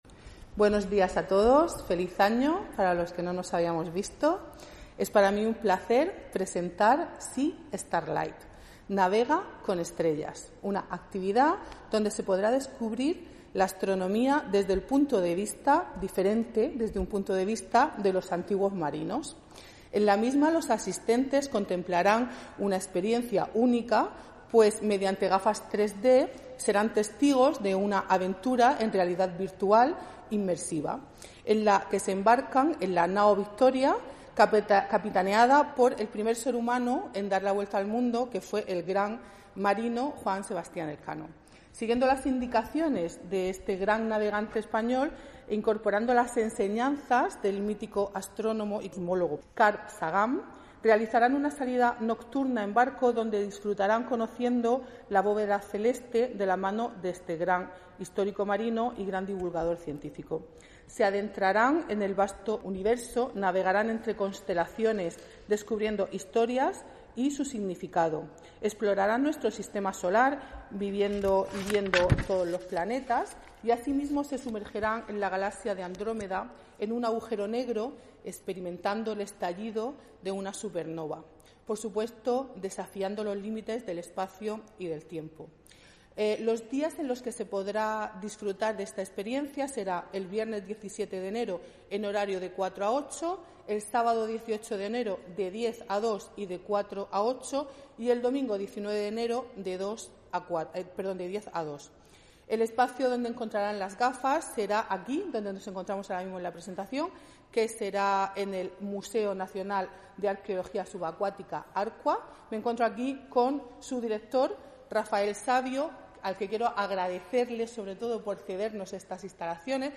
La concejal delegada de Turismo, Beatriz Sánchez del Álamo, ha presentado una actividad con la que los antiguos marinos muestran el cielo a los visitantes del Museo Nacional de Arqueología Subacuática (ARQVA) de Cartagena.